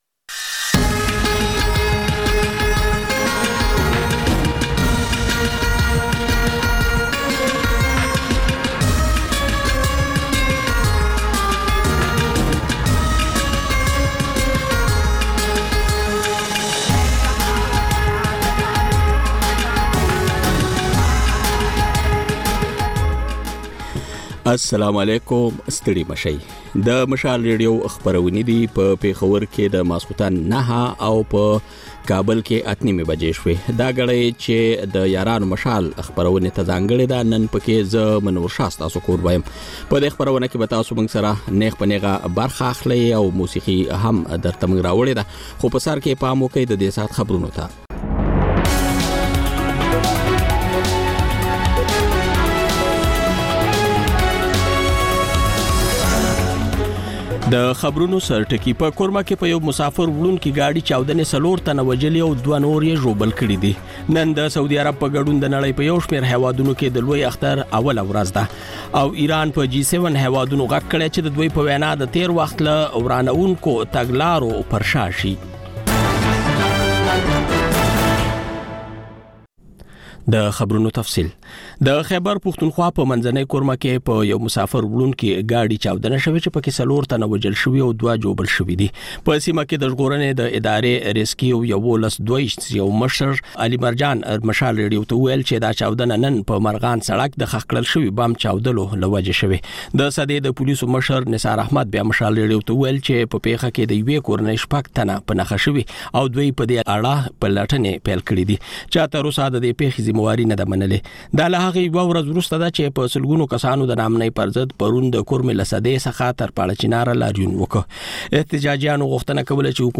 د یارانو مشال په ژوندۍ خپرونه کې له اورېدونکو سره بنډار لرو او سندرې خپروو. دا یو ساعته خپرونه هره ورځ د پېښور پر وخت د ماخوستن له نهو او د کابل پر اته نیمو بجو خپرېږي.